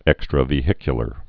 (ĕkstrə-vē-hĭkyə-lər)